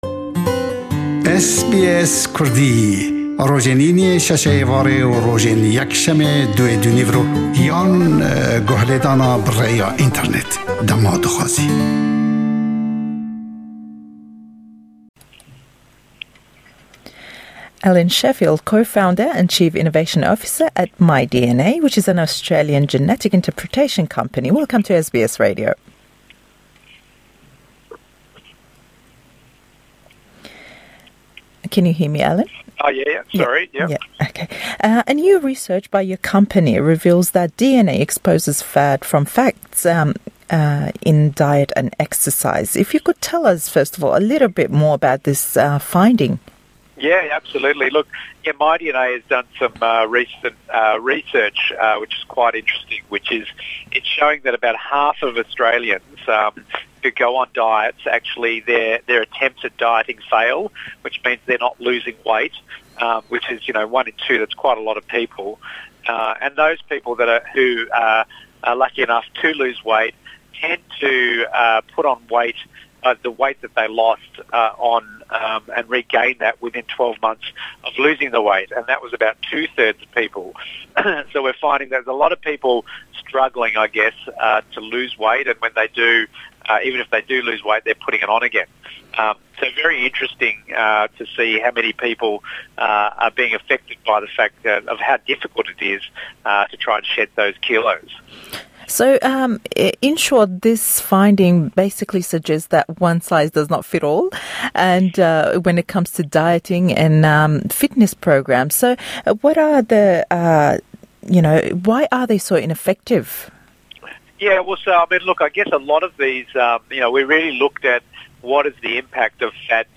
Twêjîneweykî nwê nîşandedat ke 1 le 2 hewlldanî rêjîmkirdin bo kemkirdinewey kêş serkewtû nabêt, û sê beşî ew kesaney ke kêşîyan kemdekenewe, be maweyekî kurt degerênew bo ser heman kêşî berz. Le em lêdwane da